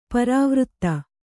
♪ parāvřtta